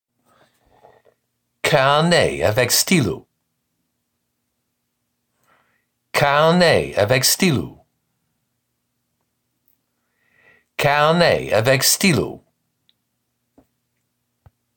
Regular French